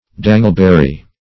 Dangleberry \Dan"gle*ber`ry\, n. (Bot.)